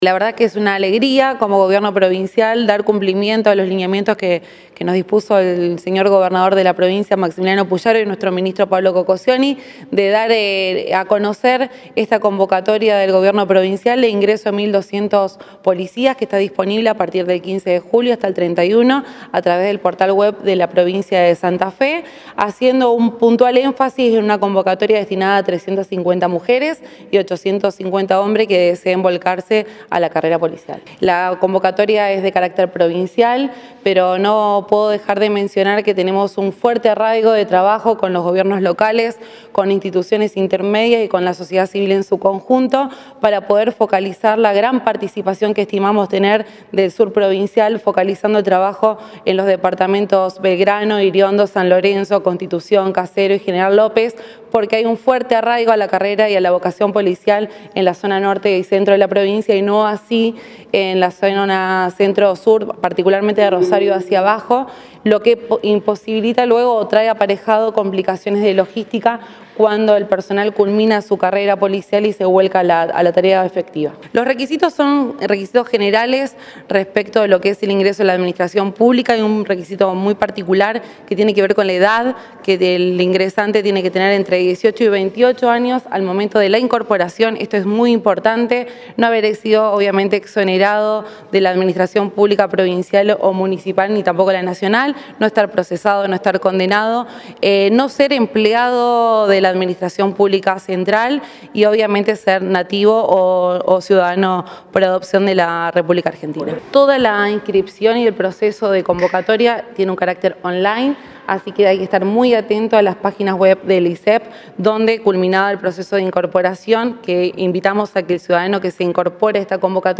Declaraciones Orciani.